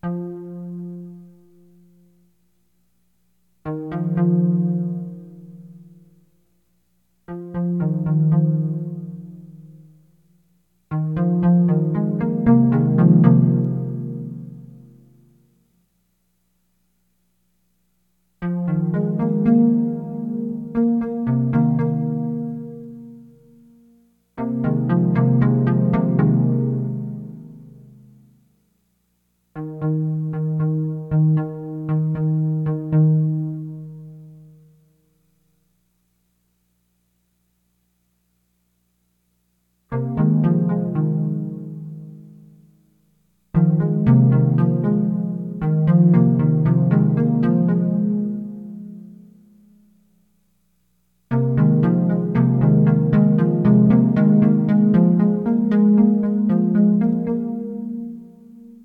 One piece, entitled …and then we dreamt of clear water until breakfast, was mostly a synthesized composition with some live and recorded solo vocals (me) at the very end.
The music was quiet, spacious and dreamy.